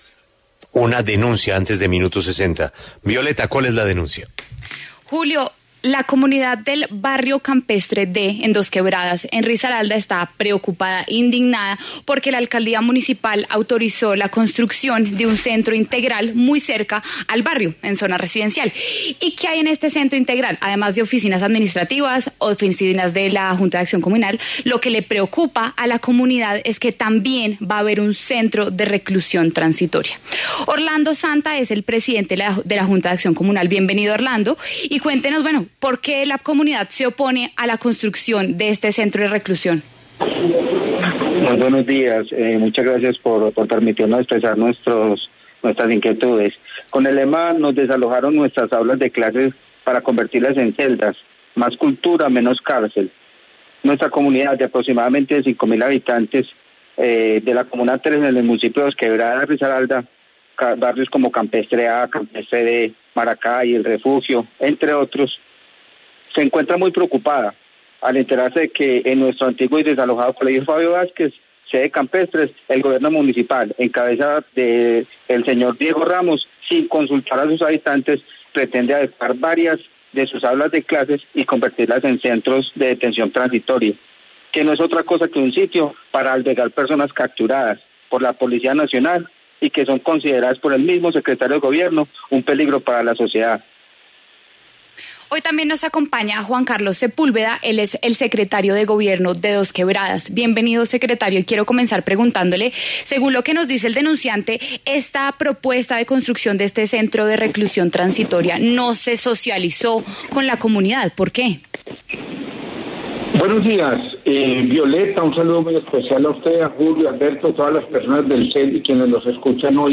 Por su parte, Juan Carlos Sepúlveda, secretario de Gobierno de Dosquebradas, advirtió que el proyecto aún se encuentra en etapa de diseños y que se ha relacionado con la comunidad.